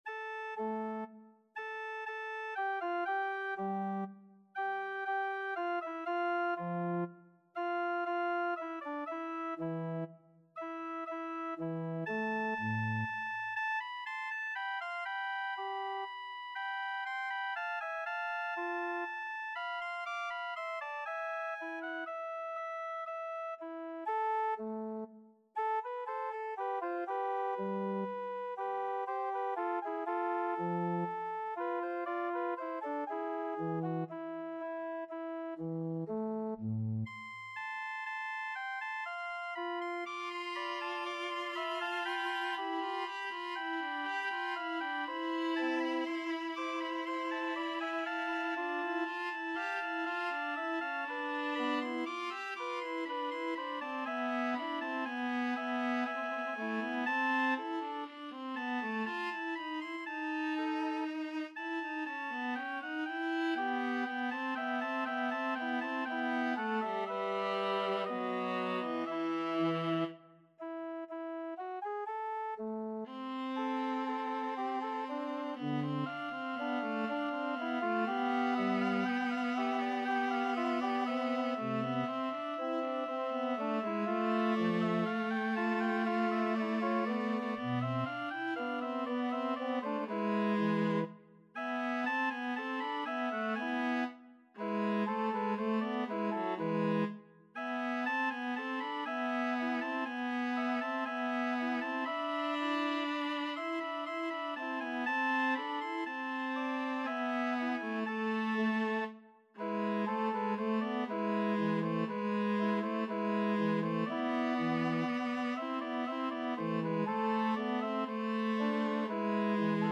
Viola version
3/4 (View more 3/4 Music)
E4-D6
Classical (View more Classical Viola Music)